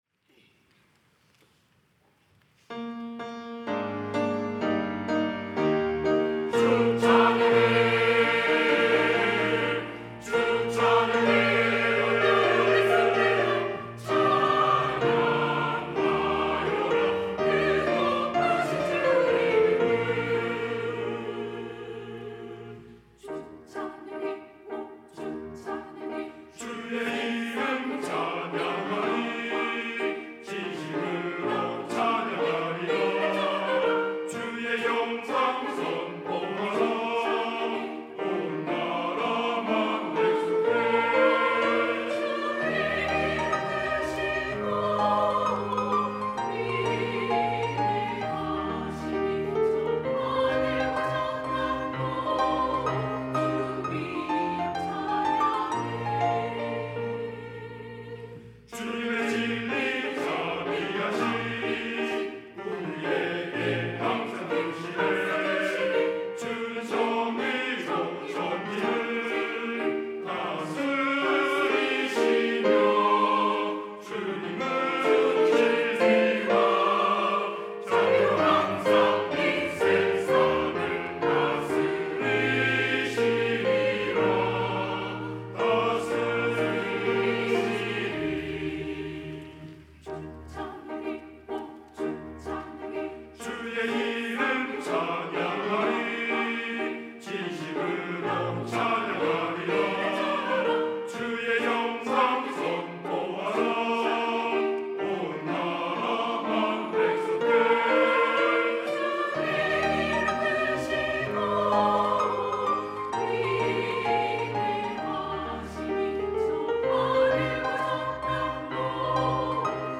시온(주일1부) - 주 찬양해
찬양대